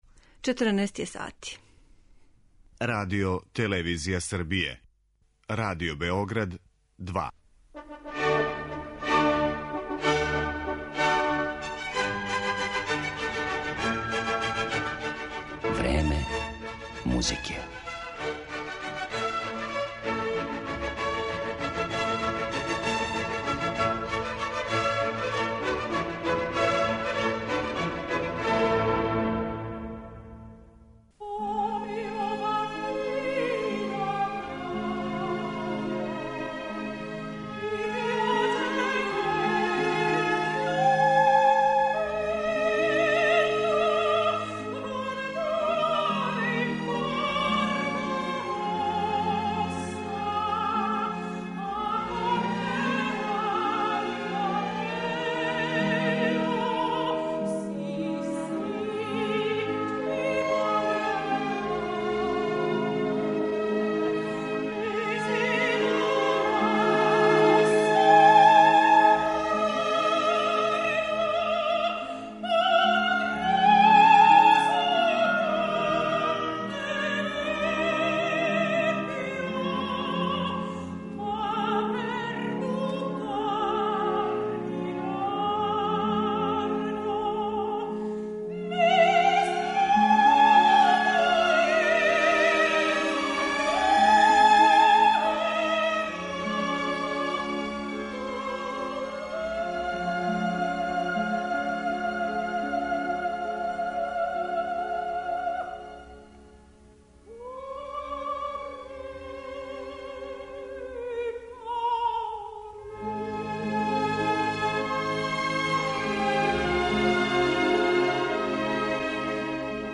Иако су неке од њених најранијих улога биле и Елза у "Лоенгрину" и Ева у "Мајсторима певачима" Рихарда Вагнера, сопран Ренате Тебалди идеално је одговарао ликовима Пучинијевих и Вердијевих опера, као што су Тоска, Мими, Виолета или Дездемона.
Управо арије ових трагичних хероина чине део музичког садржаја емисије